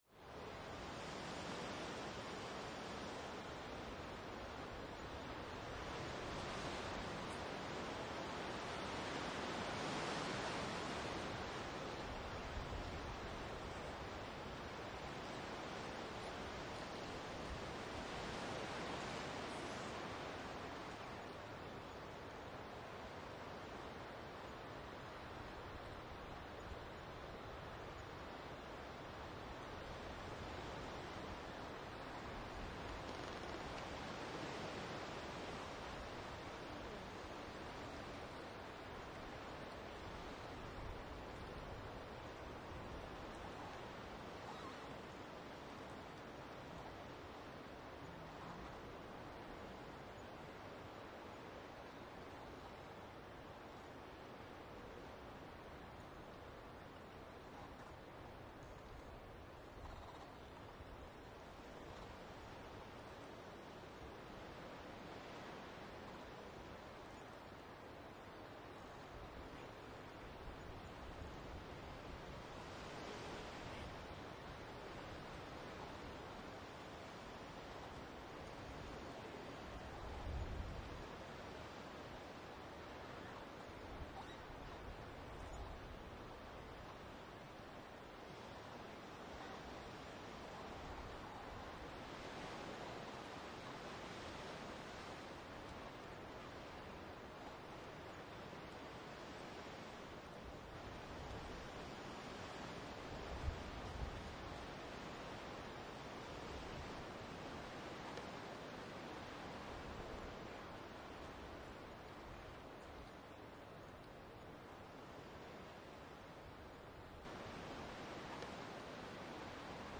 阿根廷, 巴塔哥尼亚 " 森林中的风的气氛
描述：大风，树在森林里略微吱吱作响。 （南阿根廷）